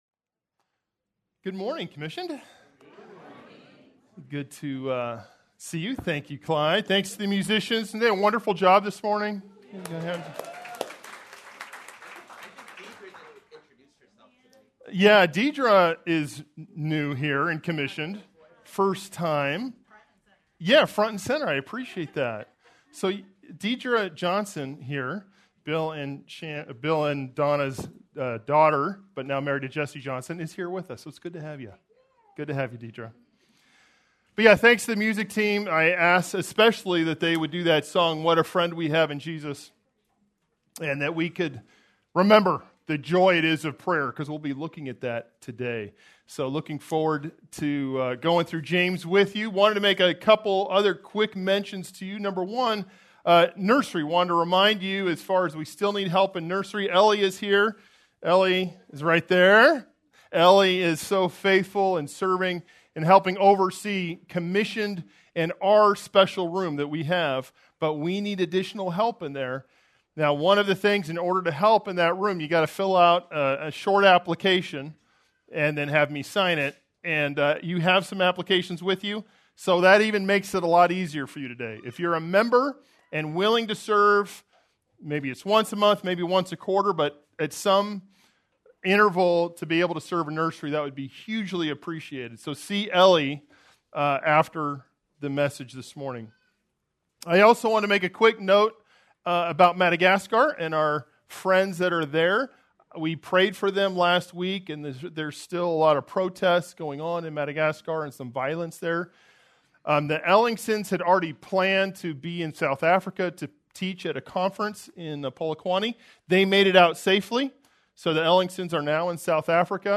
Due to technical difficulties, this sermon is incomplete.